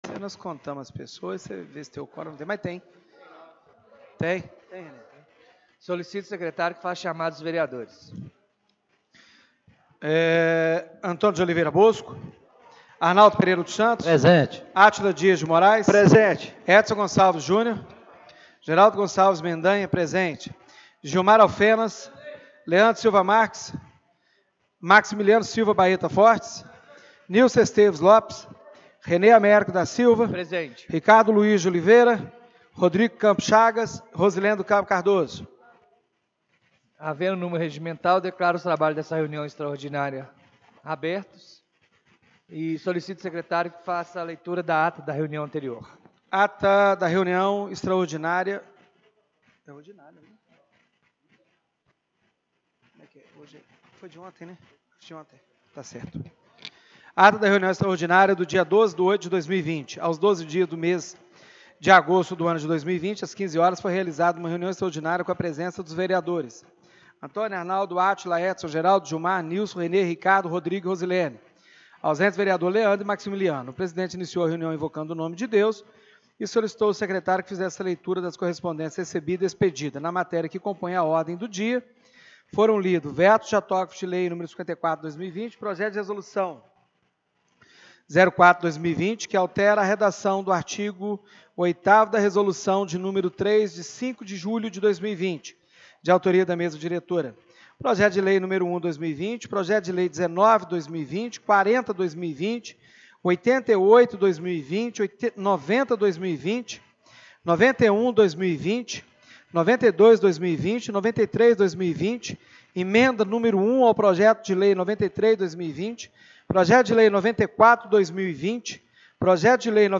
Reunião Extraordinária do dia 13/08/2020